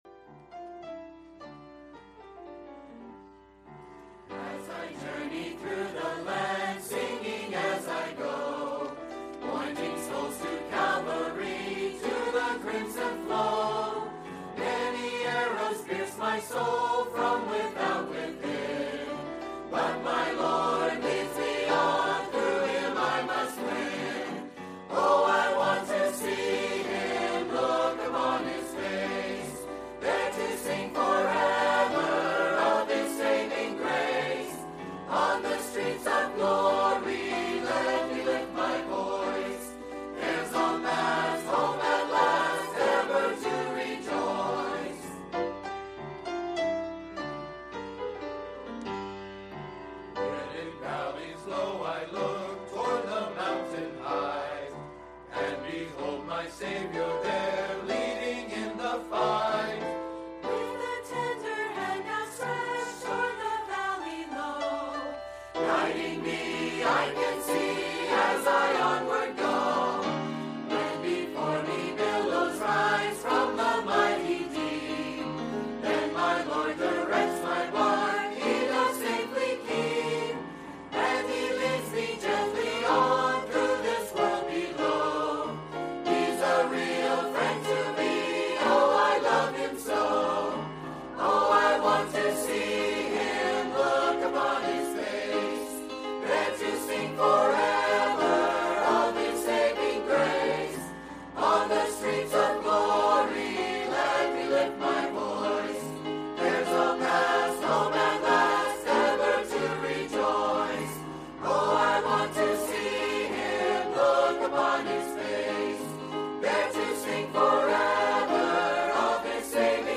Sermons – Pioneer Baptist Church of Citrus Heights, CA